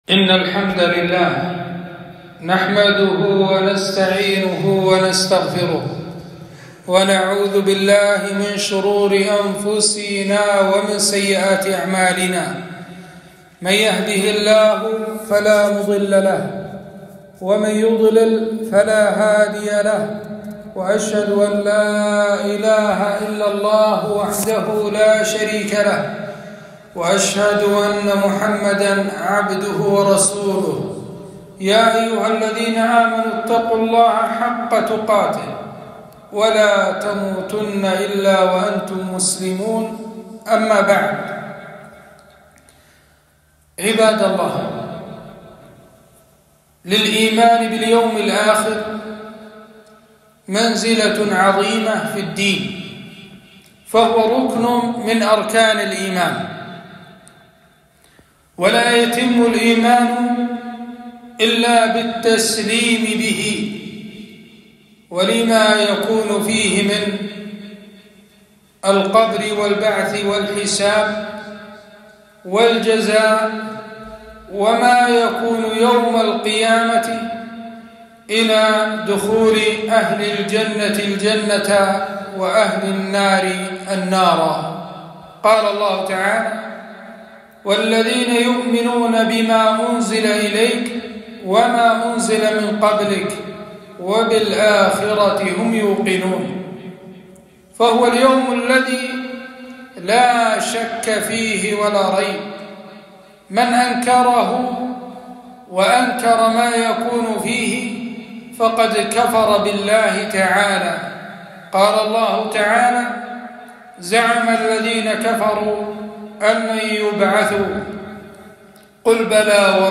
خطبة - الميزان يوم القيامة